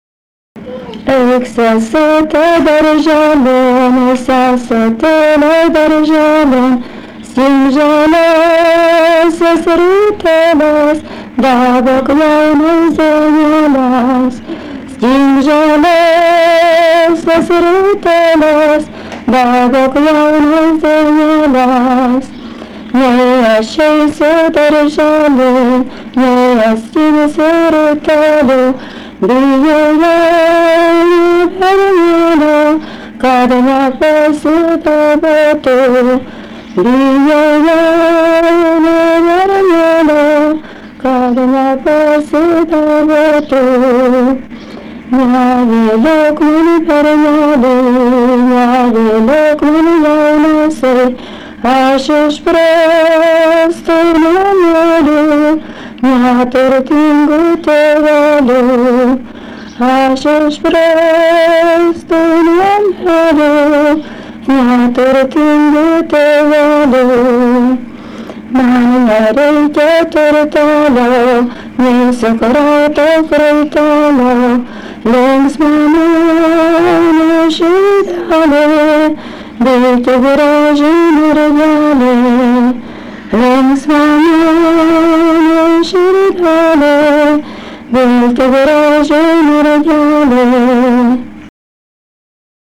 daina
vokalinis